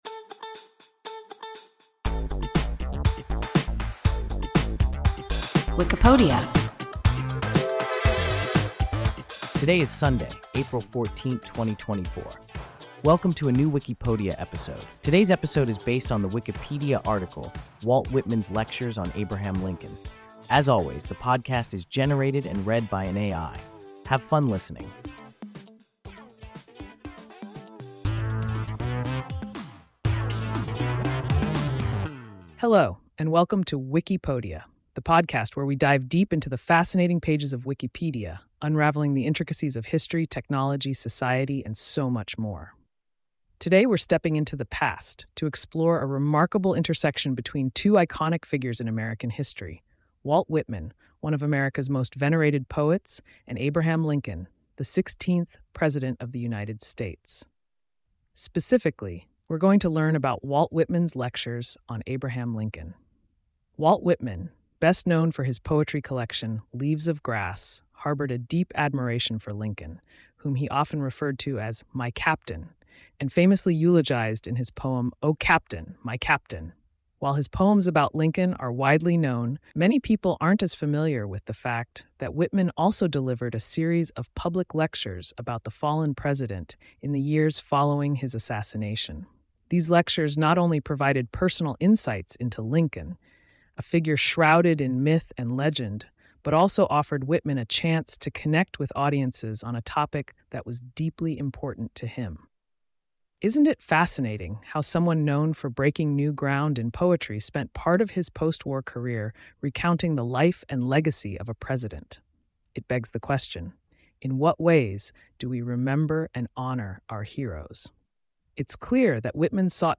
Walt Whitman’s lectures on Abraham Lincoln – WIKIPODIA – ein KI Podcast